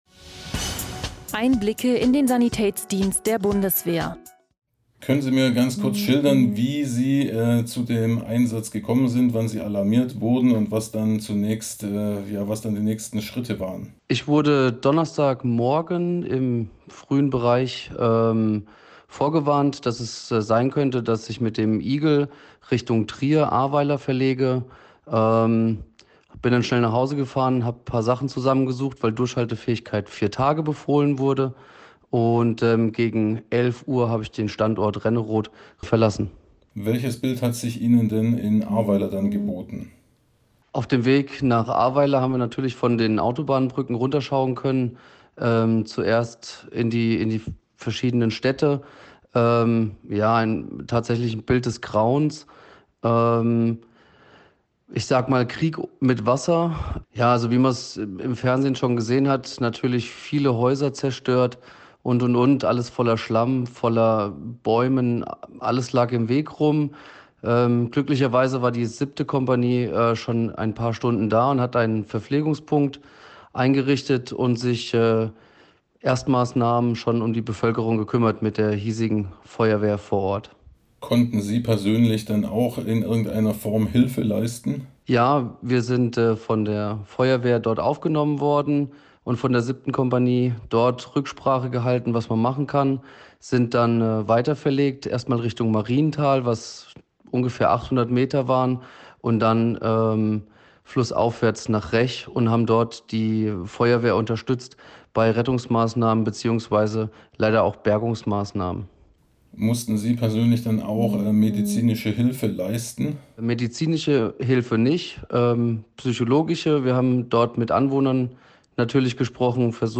Im Audio-Interview schildert er seine Eindrücke.
aus-dem-flutkatastrophengebiet-im-ahrtal-data.mp3